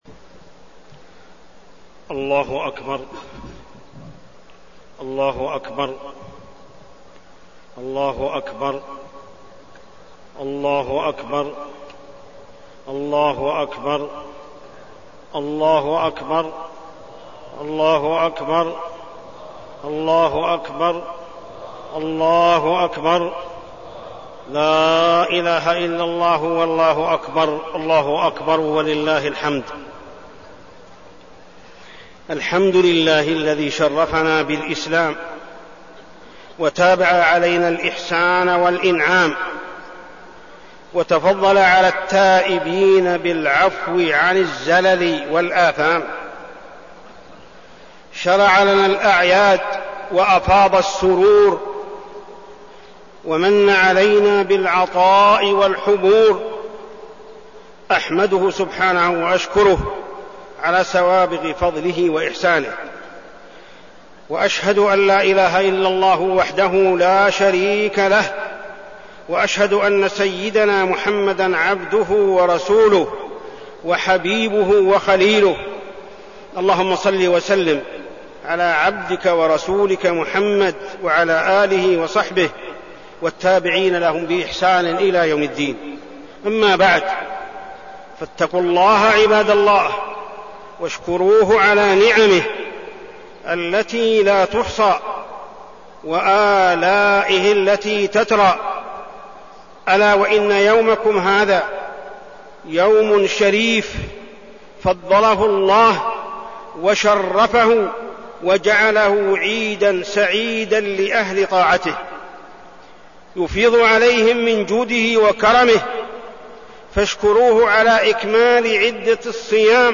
خطبة عيد الفطر -الغلو في الدين
تاريخ النشر ١ شوال ١٤١٧ هـ المكان: المسجد الحرام الشيخ: محمد بن عبد الله السبيل محمد بن عبد الله السبيل خطبة عيد الفطر -الغلو في الدين